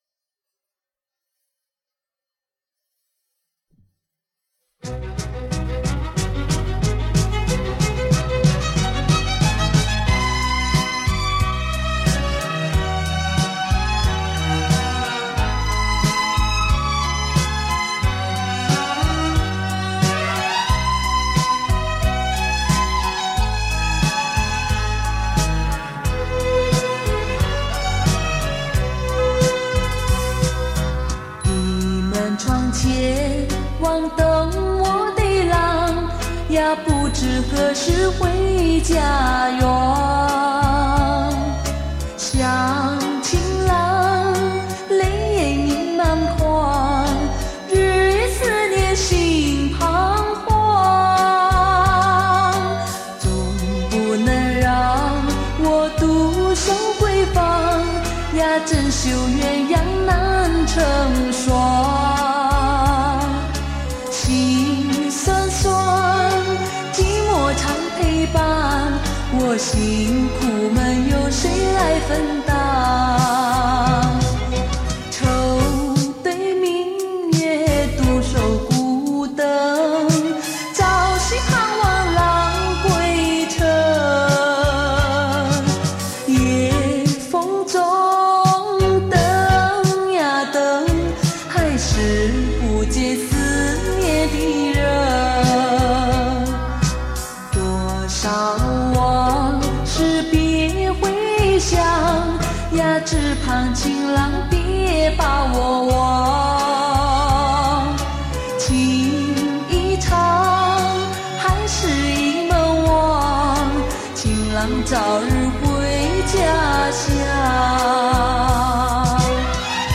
东南亚酒廊情歌